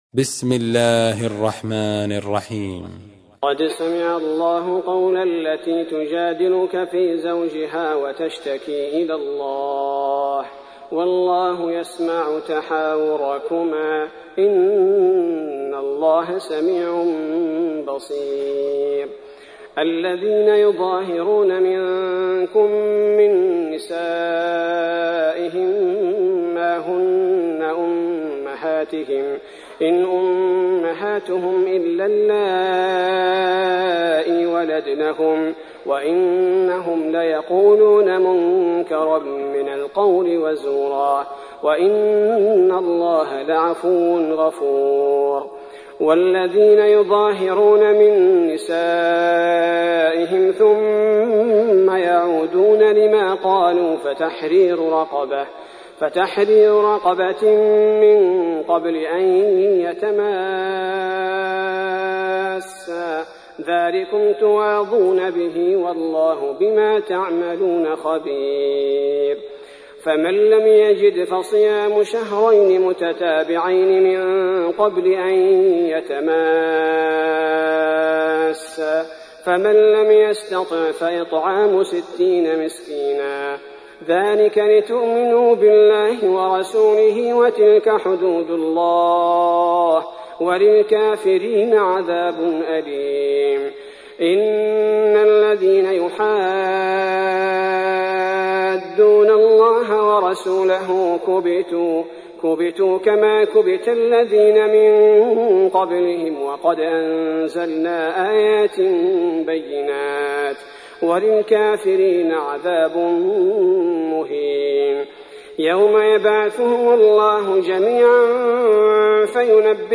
تحميل : 58. سورة المجادلة / القارئ عبد البارئ الثبيتي / القرآن الكريم / موقع يا حسين